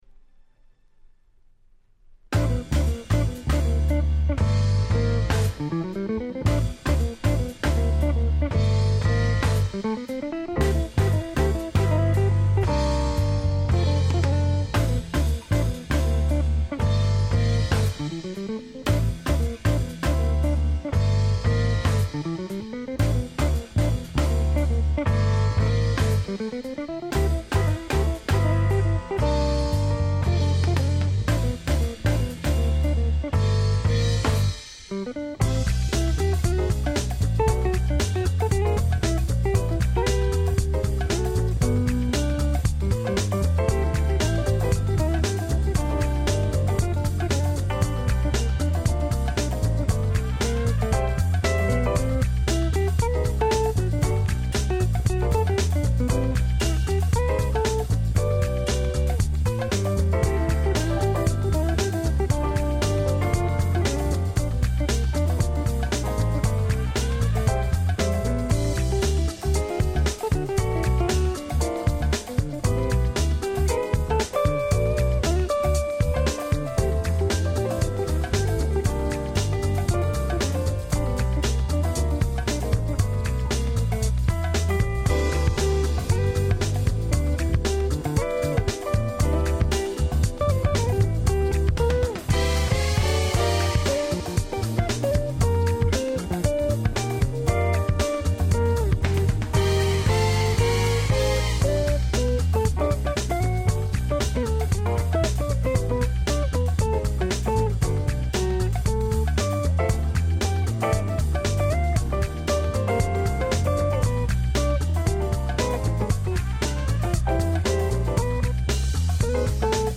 93' Nice Acid Jazz LP !!